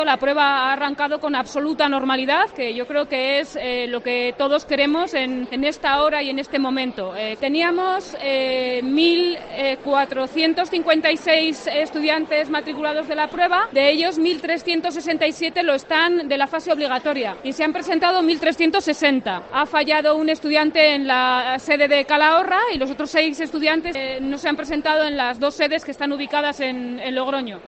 La directora general de Universidad y Política Científica del Gobierno de La Rioja, Pilar Vargas, explica que la EBAU ha comenzado, afortundamente, con normalidad.